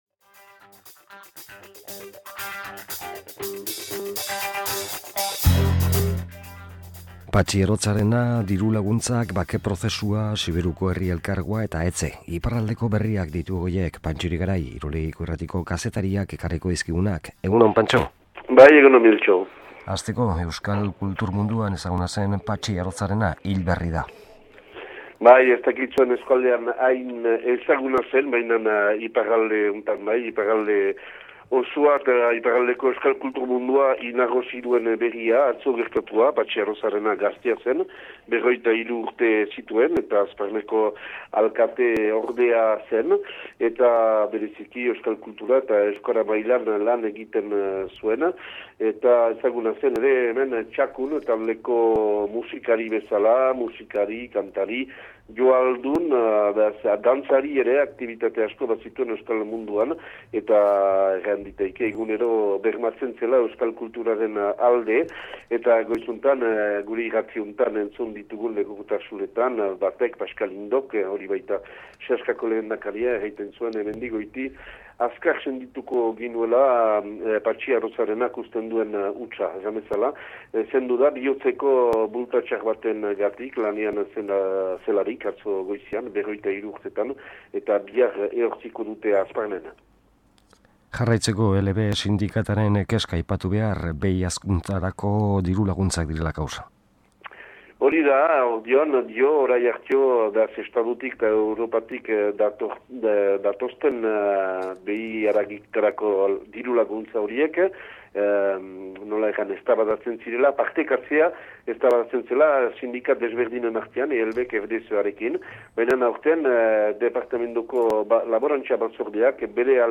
IBAIZABAL: Iparraldeko kronika | Bilbo Hiria irratia